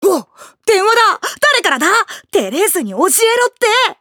【ヘレンローゼ：渡辺明乃】